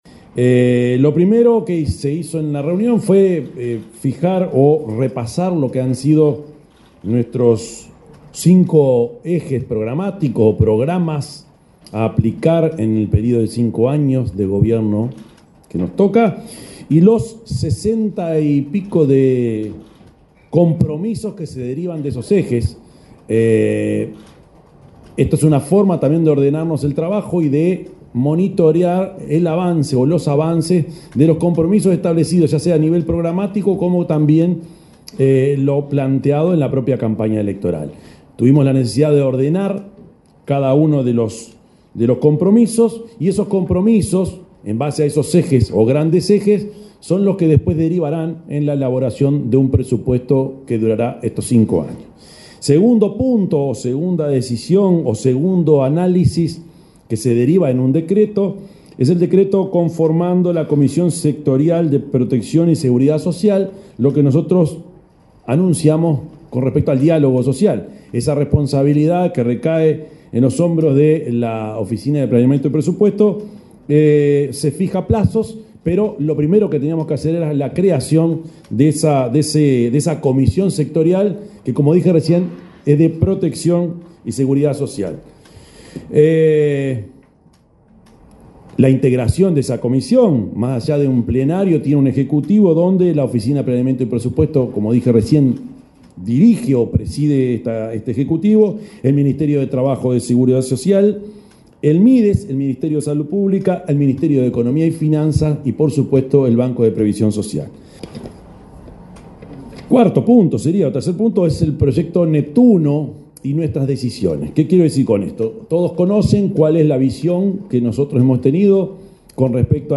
El presidente de la República, profesor Yamandú Orsi, informó en conferencia de prensa, luego del Consejo de Ministros, que el Gobierno definió conformar una comisión sectorial de protección y seguridad social.